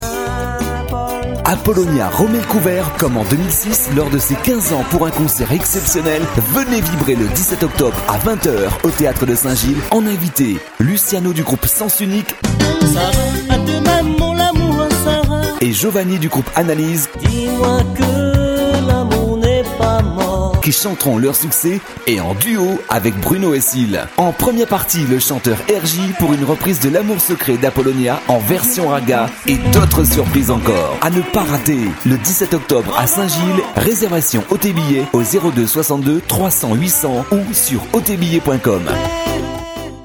Les Spots Pub Radio
le spot pour St Gilles.